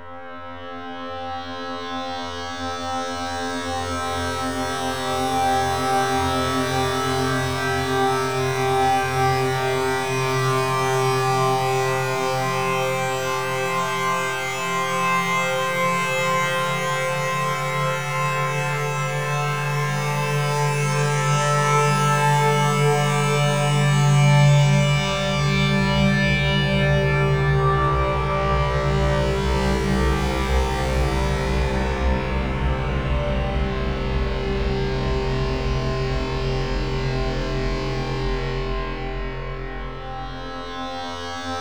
MOOG3AD.wav